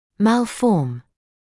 [ˌmæl’fɔːm][ˌмэл’фоːм]формировать аномальный образом; деформировать